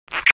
kiss1.wav